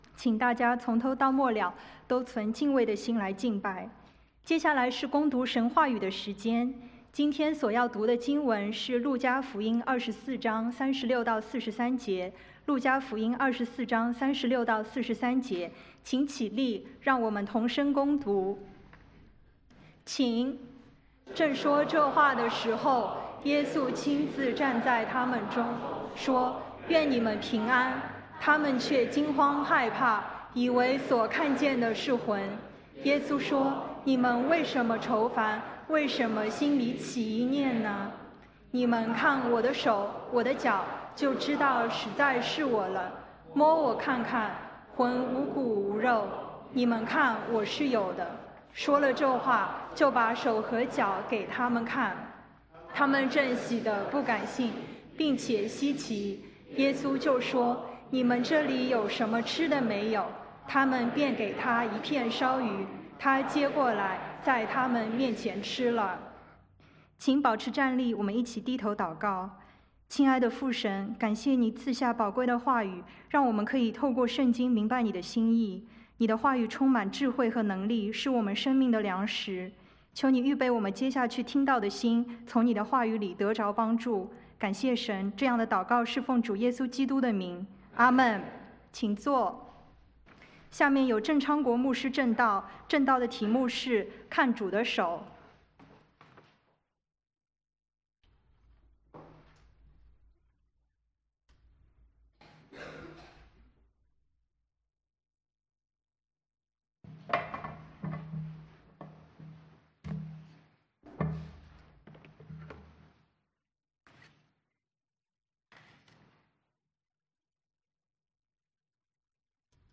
2018 主日證道